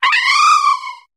Cri de Lakmécygne dans Pokémon HOME.